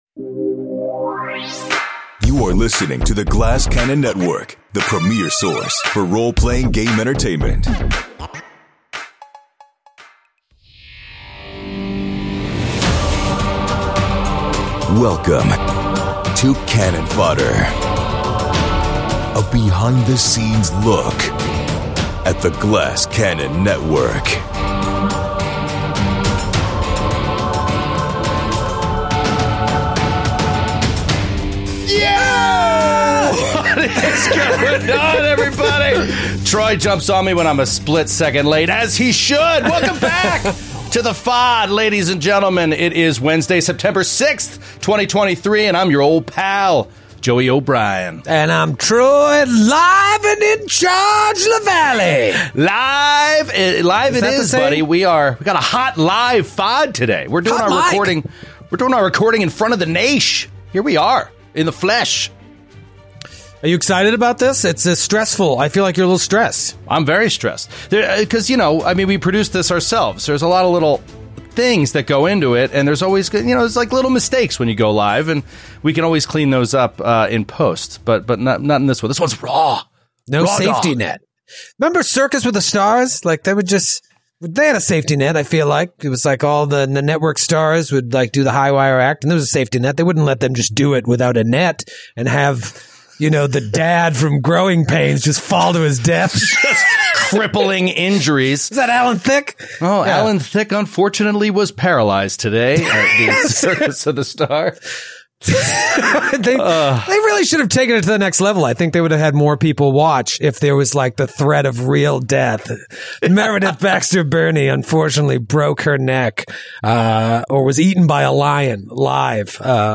In We Are Stupid, the guys look back at the epic ending of Side Quest Side Sesh Season 2 and analyze the finale from top to bottom. Plus, since this one was live, we brought back a little Listener Mail!